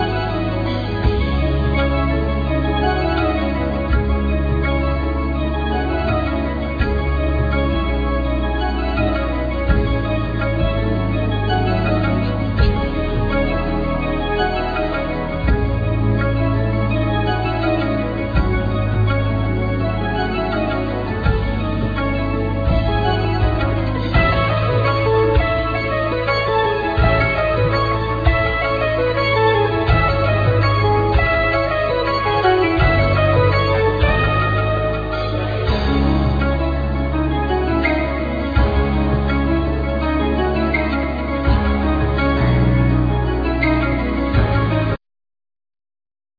Guitar,Guitar Synth,Programming
Drums,Percussion